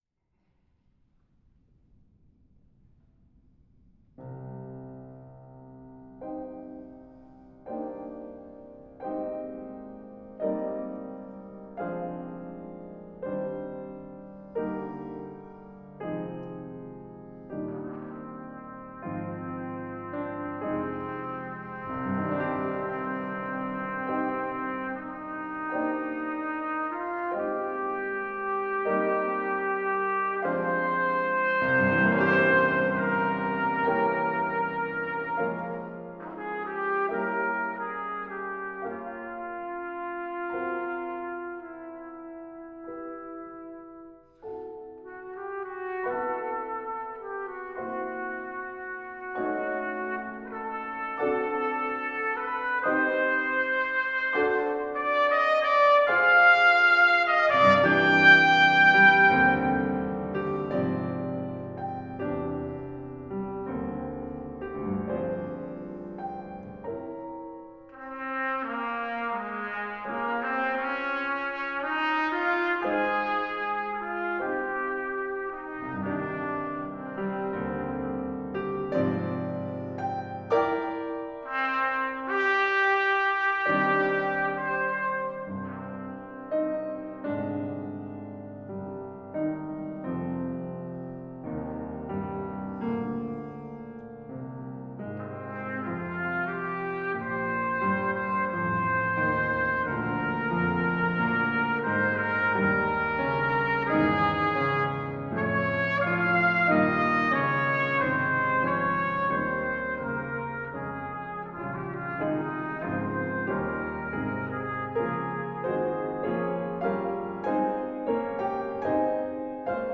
Recordings of select live performances
St. Stephens Episcopal, Seattle
piano